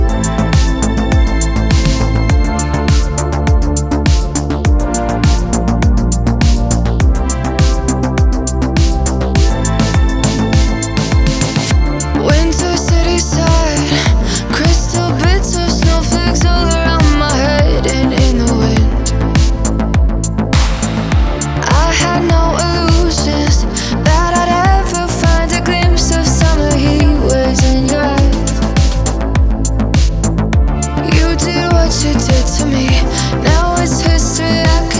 Жанр: Танцевальные
Dance